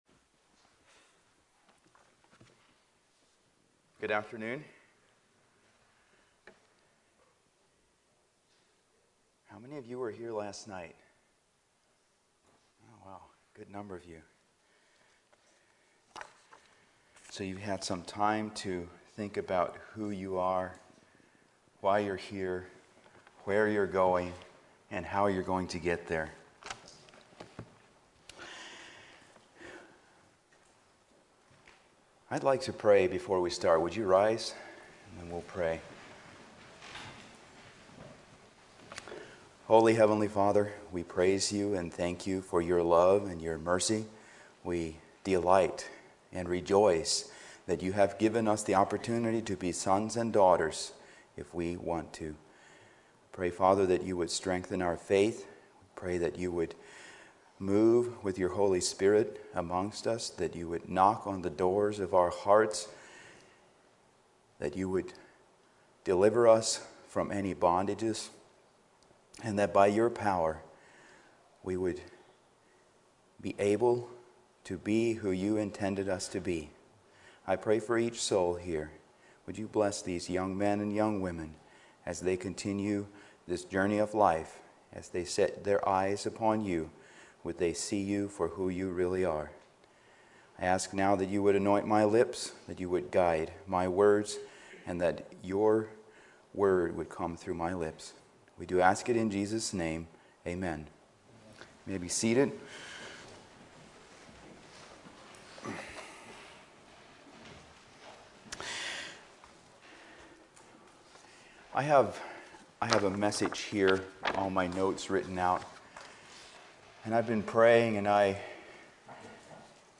2024 Youth Meetings , Youth Meeting Messages